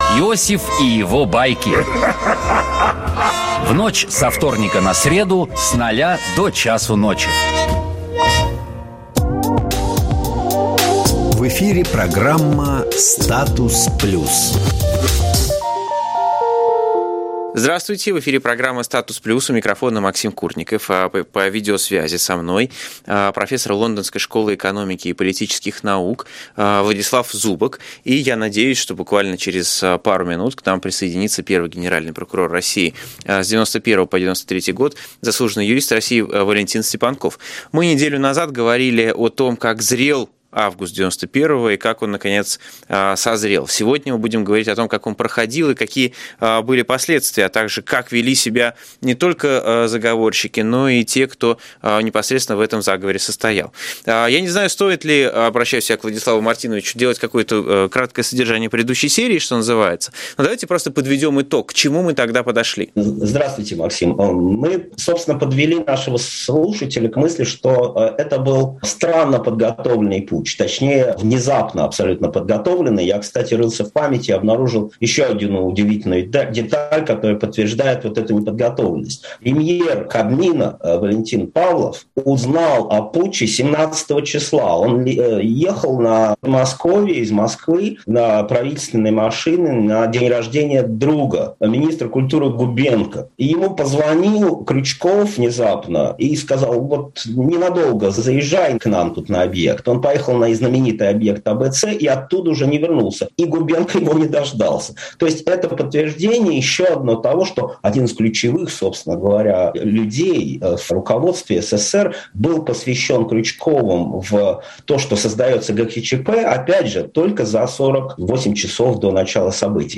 Из архива “Эха Москвы”. 17 августа 2021 года.